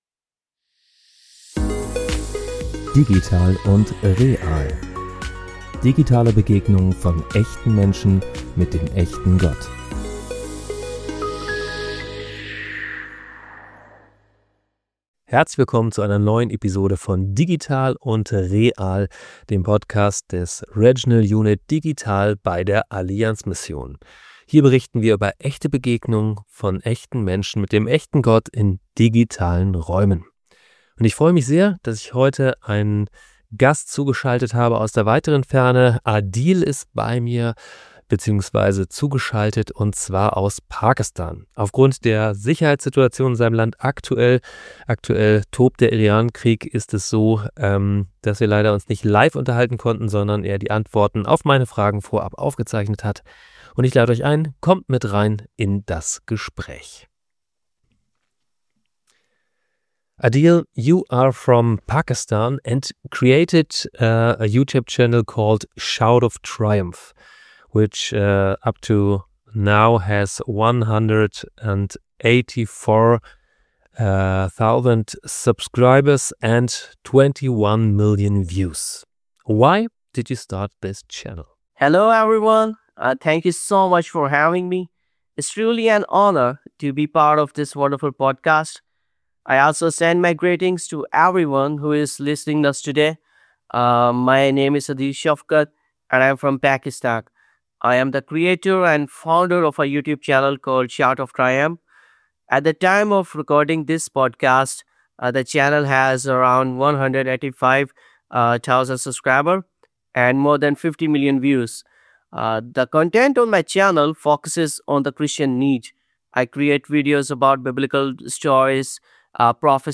Das Gespräch ist auf Englisch.